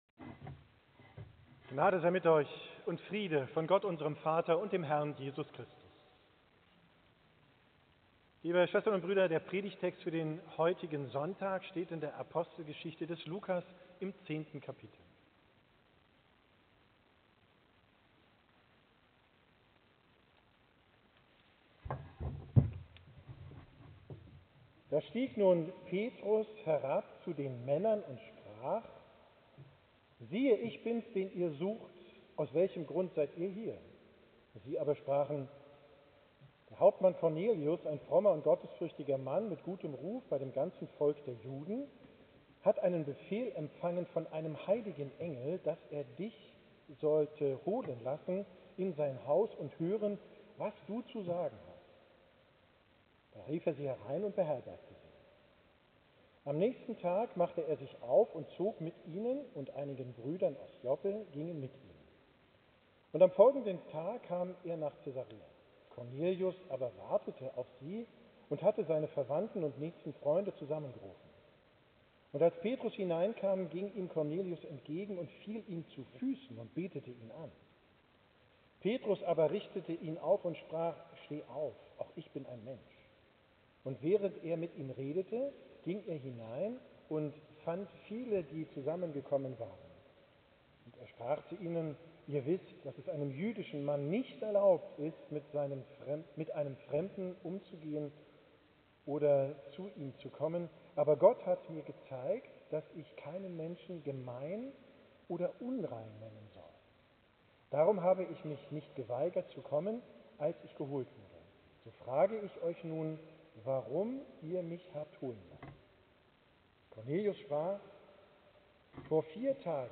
Predigt vom 3.